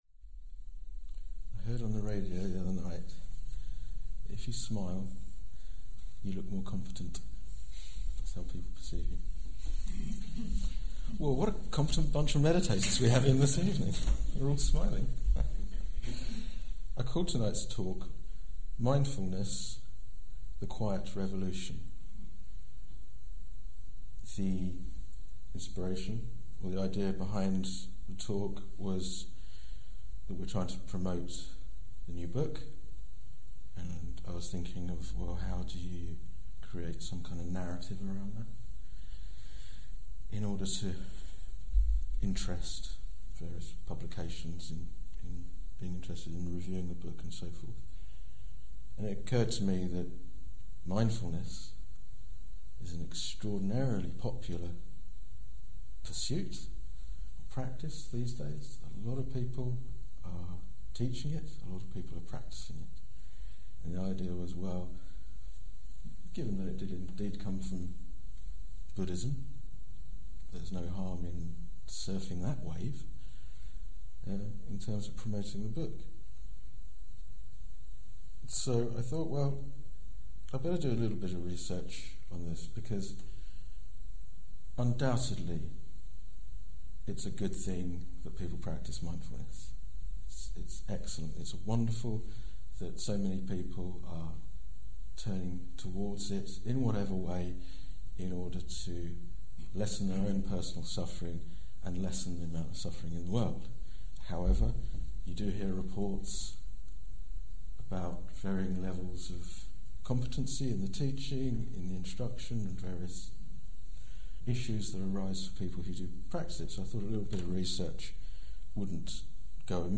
This talk was given in October 2015